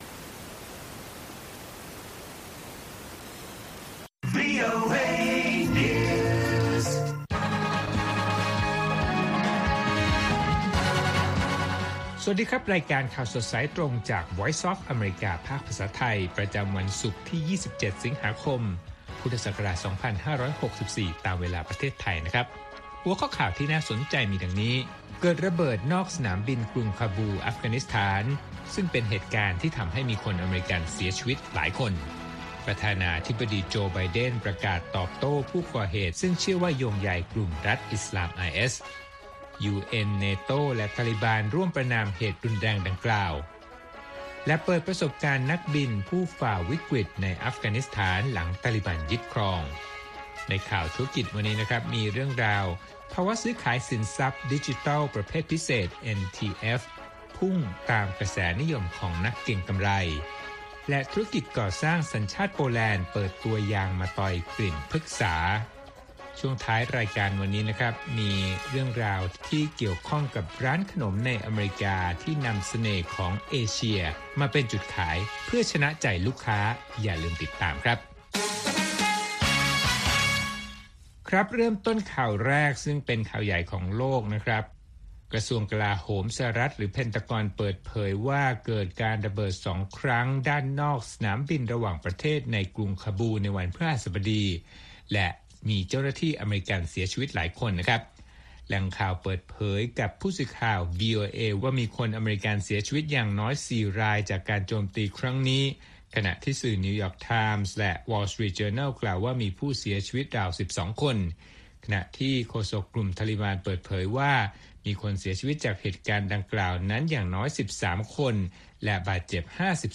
ข่าวสดสายตรงจากวีโอเอ ภาคภาษาไทย ประจำวันศุกร์ที่ 27 สิงหาคม 2564 ตามเวลาประเทศไทย